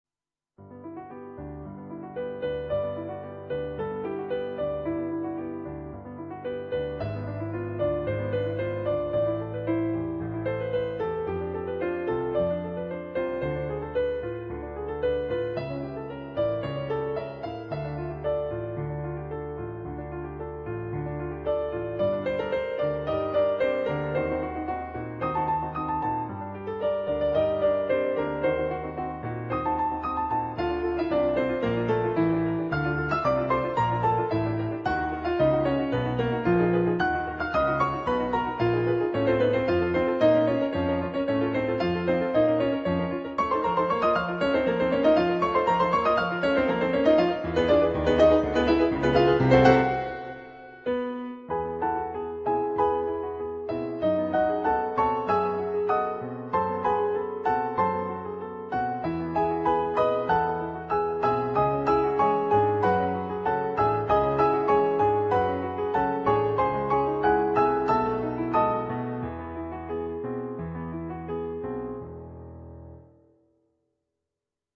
adapted for Two Pianos
on Yamaha digital pianos.